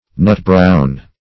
Nut-brown \Nut"-brown`\, a.
nut-brown.mp3